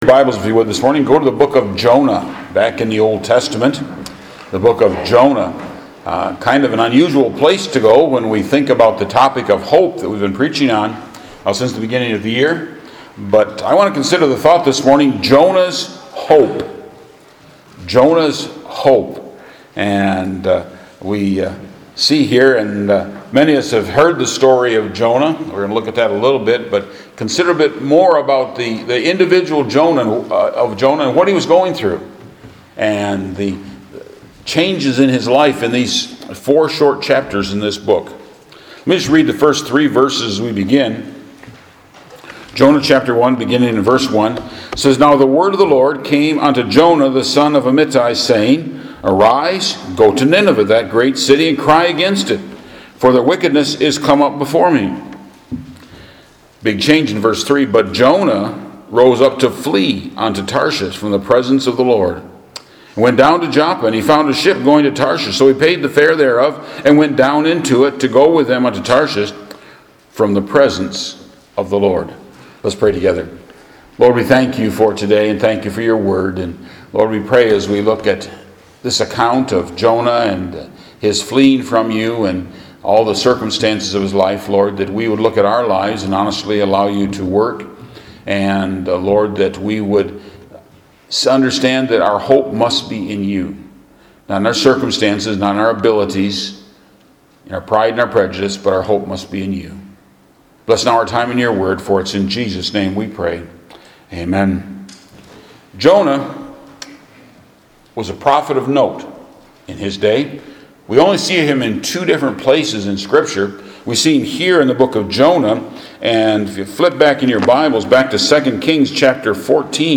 Sermon MP3